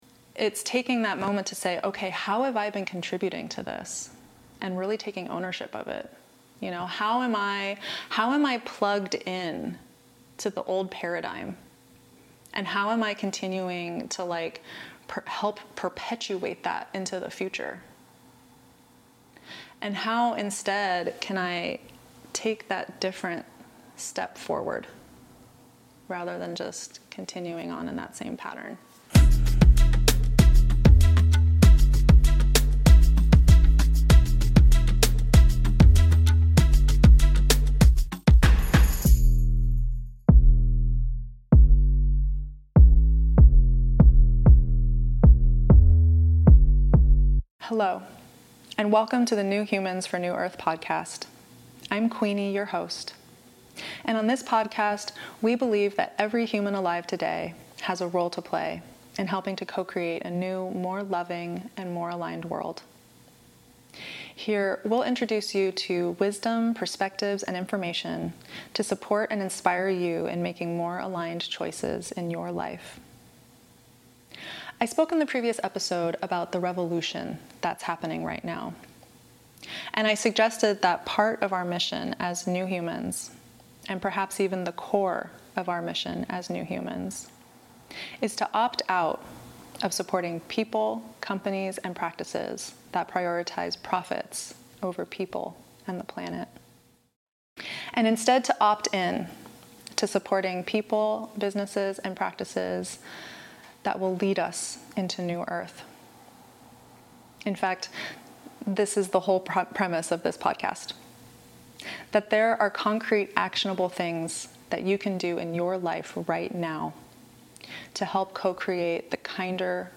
This is an honest conversation about awakening without bypassing.